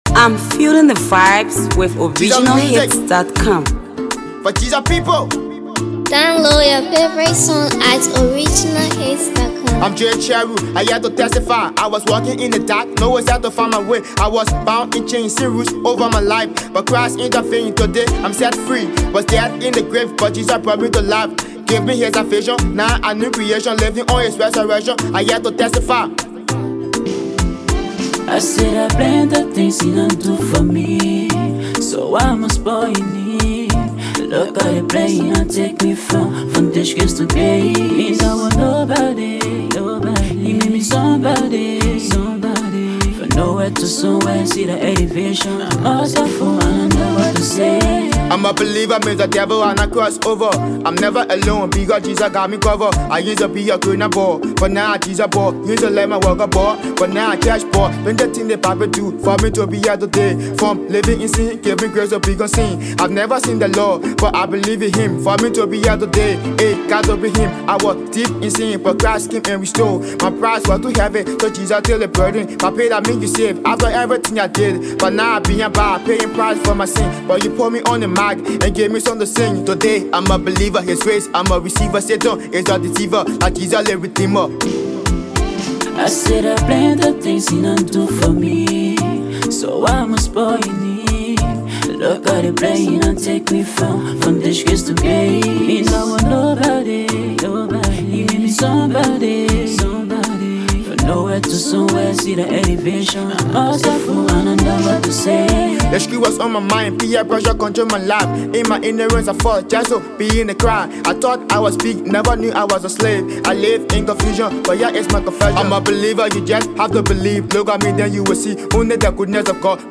A heartfelt gospel song